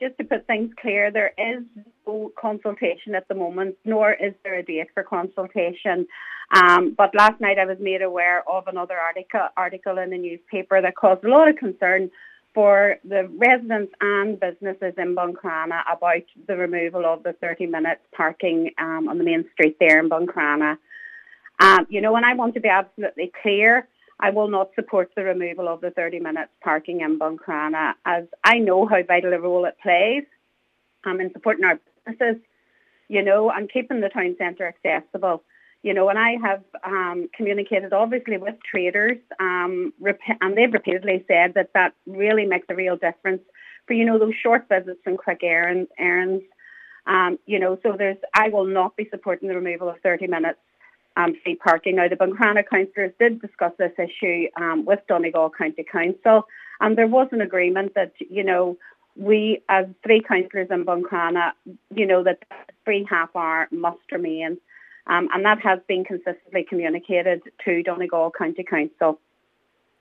Cllr Joy Beard says she’ll oppose any move to change the free thirty minutes: